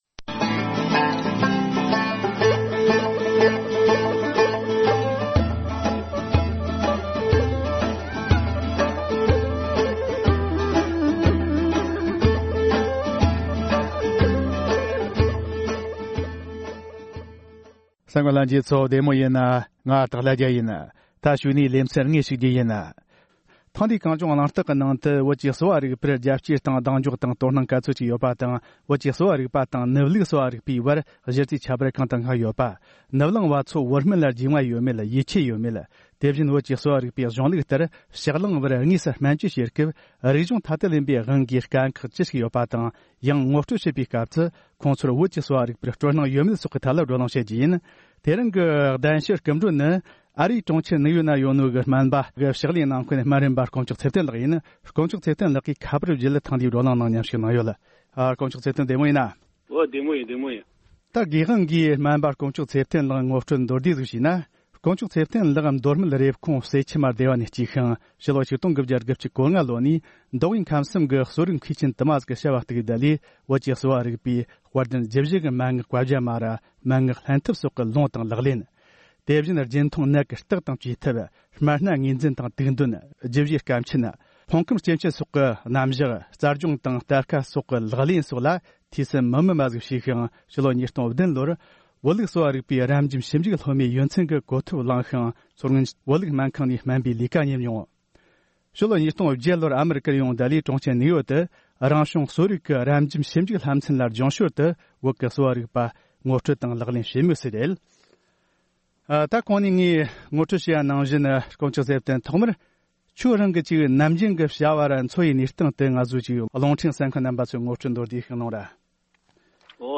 བོད་ལུགས་དང་ནུབ་ལུགས་གསོ་བ་རིག་པའི་གཞི་རྩའི་ཁྱད་པར་དང་ནུབ་གླིང་བ་ཚོས་བོད་སྨན་ལ་རྒྱུས་མངའ་དང་ཡིད་ཆེས་ཡོད་མེད་ཐད་གླེང་མོལ།